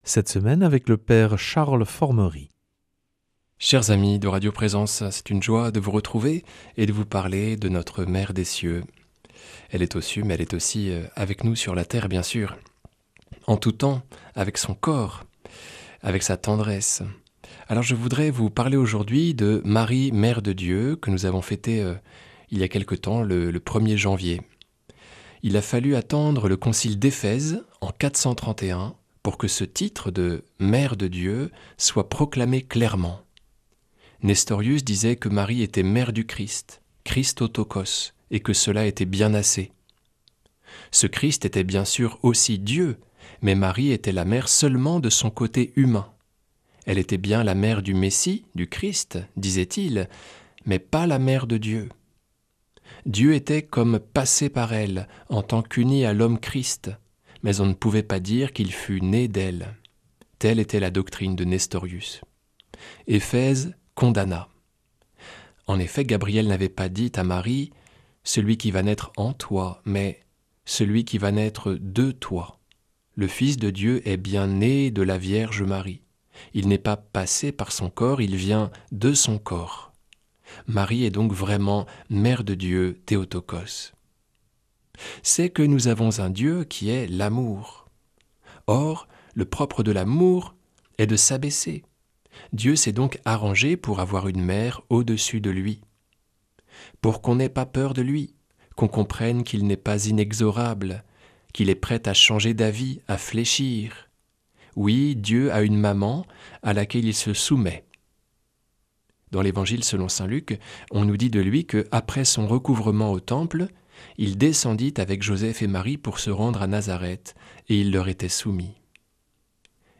lundi 26 janvier 2026 Enseignement Marial Durée 10 min